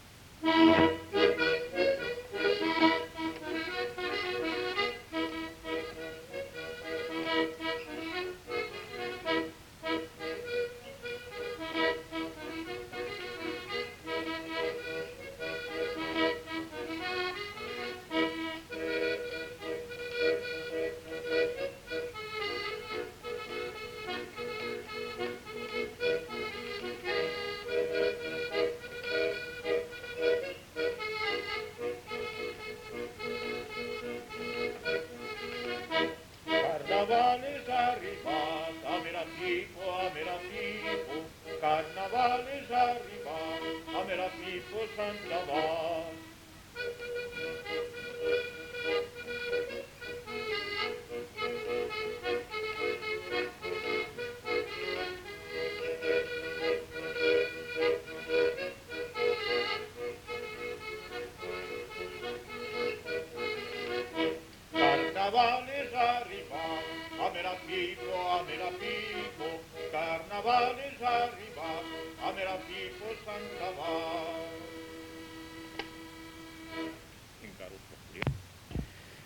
Lieu : Villardonnel
Type de voix : voix d'homme
Production du son : chanté
Instrument de musique : accordéon diatonique
Classification : chanson de carnaval